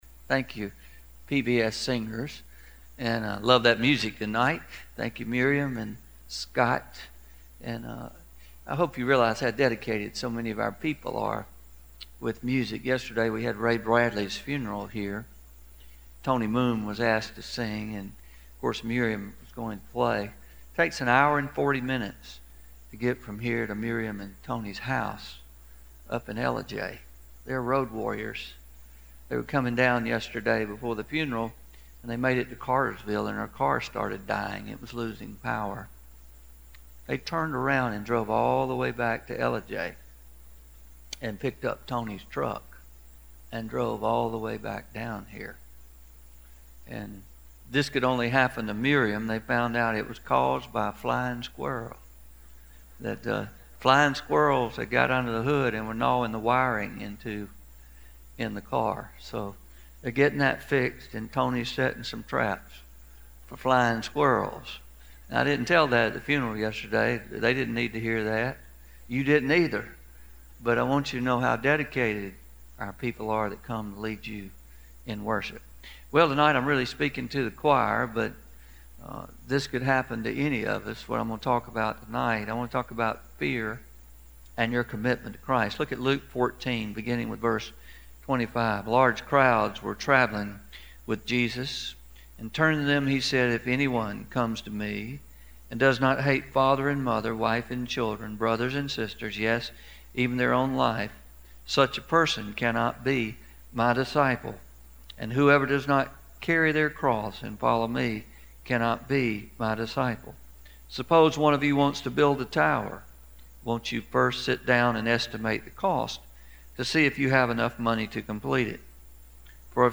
11-11-18pm Sermon – Fear and Your Commitment